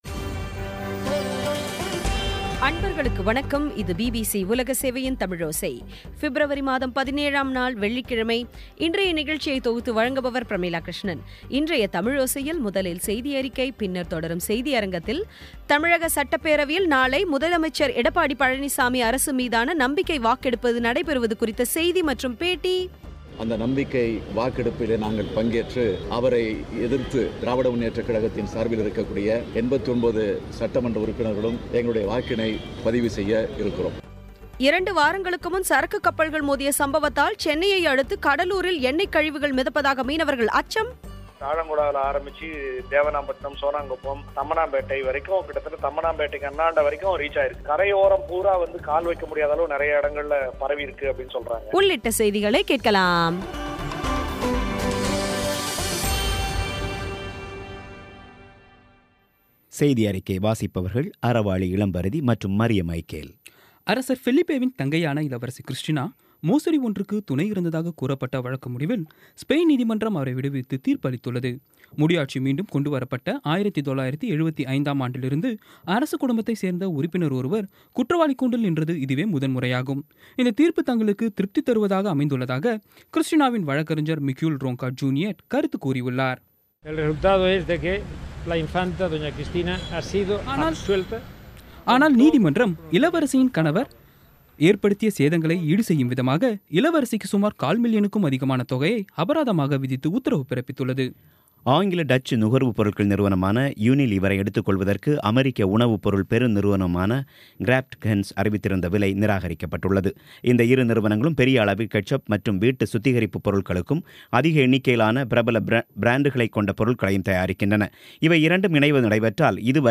இன்றைய தமிழோசையில், முதலில் செய்தியறிக்கை, பின்னர் தொடரும் செய்தியரங்கத்தில், தமிழக சட்டப்பேரவையில் நாளை முதலமைச்சர் எடப்பாடி பழனிச்சாமி அரசு மீதான நம்பிக்கை வாக்கெடுப்பு நடைபெறுவது குறித்த செய்தி மற்றும் பேட்டி இரன்டு வாரங்களுக்கு முன் சரக்கு கப்பல்கள் மோதிய சம்பவத்தால் சென்னையை அடுத்து கடலூரில் எண்ணெய் கழிவுகள் மிதப்பதாக மீனவர்கள் அச்சம் ஆகியவை கேட்கலாம்